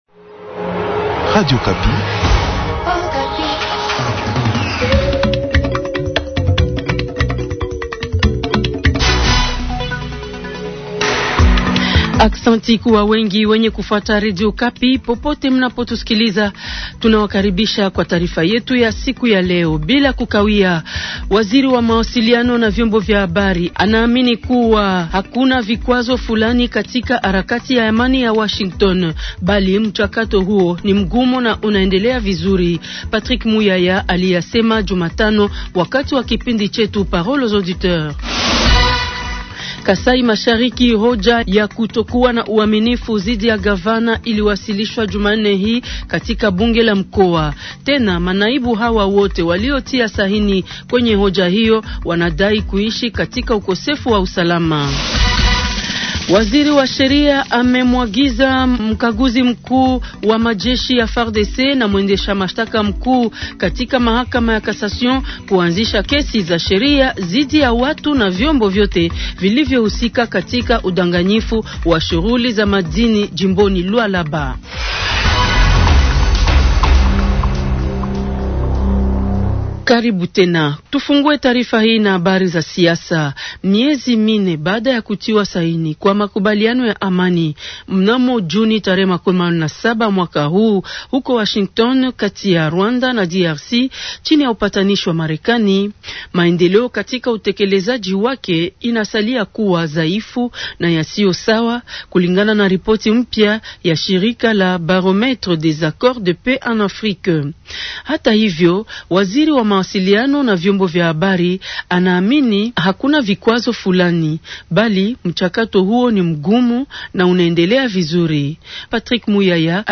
Journal swahili SOIR du mercredi 29 octobre 2025.